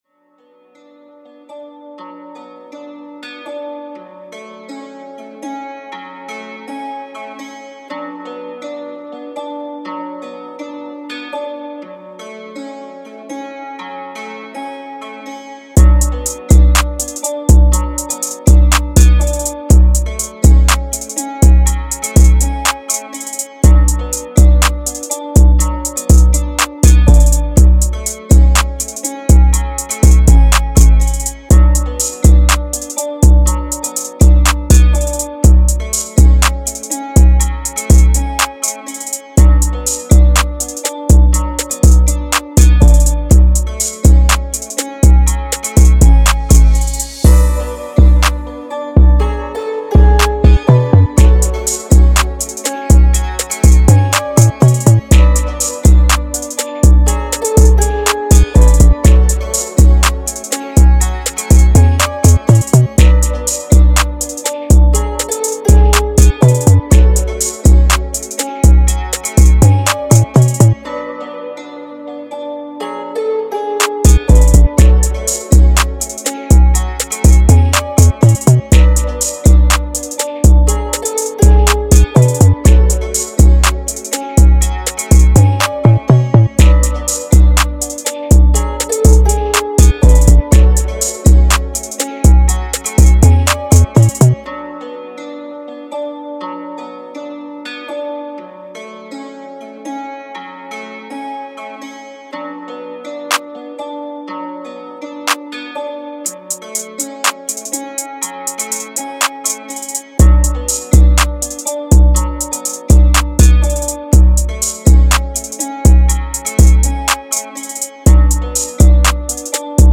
nabízím svoje zkušenosti s rap/trap hudbou v beatu